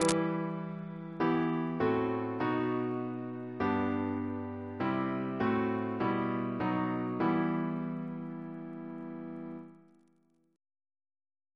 Single chant in E minor Composer: Philip Hayes (1738-1797) Reference psalters: ACB: 43